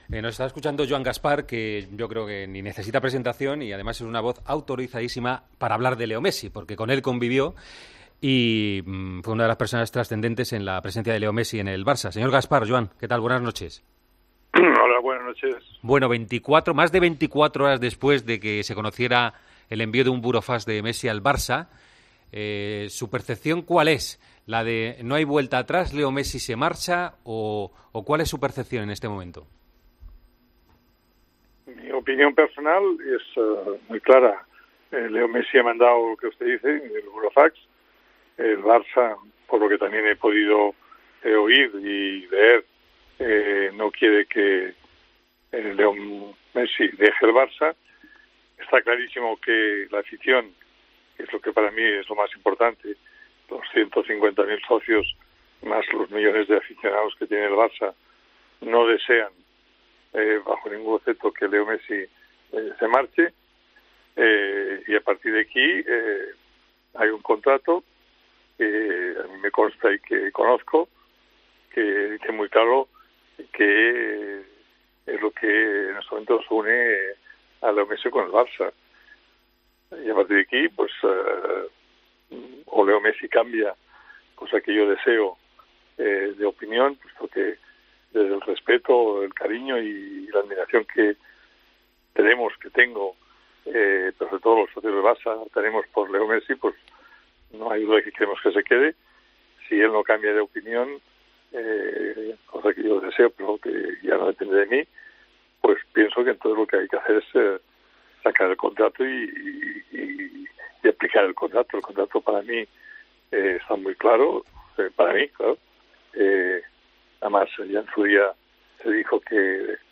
El ex presidente del FC Barcelona Joan Gaspart ha visitado El Partidazo de COPE para analizar y valorar la salida de Leo Messi